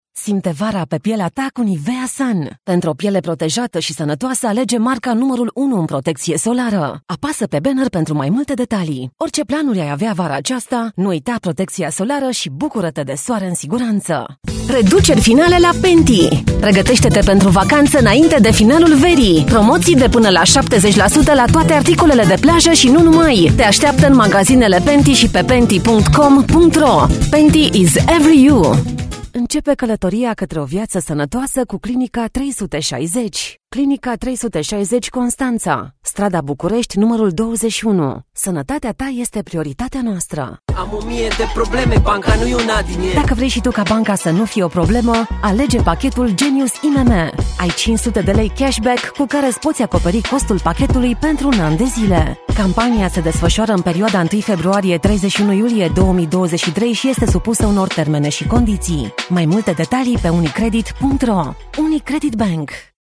Get the distinctive native Romanian voice artist for your project that stays on people's minds and has them telling others about it.
Sprechprobe: Werbung (Muttersprache):
PITCH: mid-range, female, 30-50 yrs. TONE: dynamic, relatable, pleasant ACCENT: neutral Romanian, English with an Eastern European Accent My custom-built home studio is fully connected for directed sessions, I record with a NEUMANN TLM 107 Microphone and Scarlet2i2 generation 4 interface.